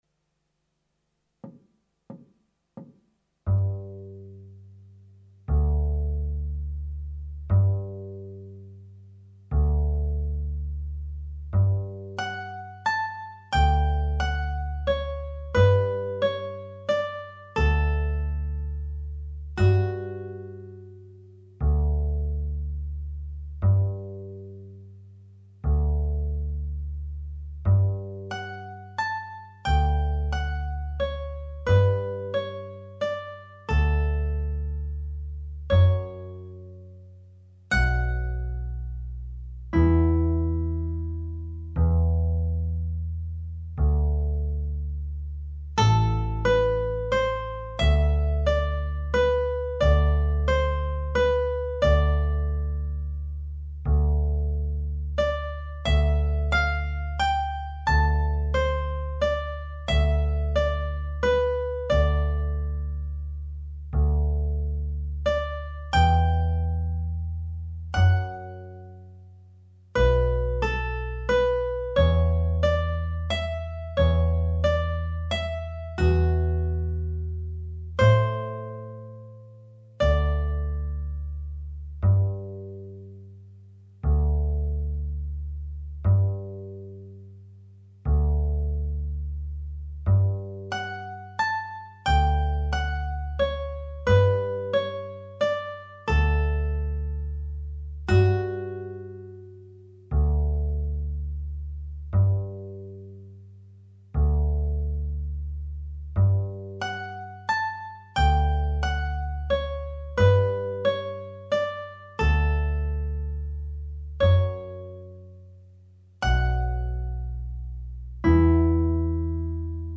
minus Guitar 1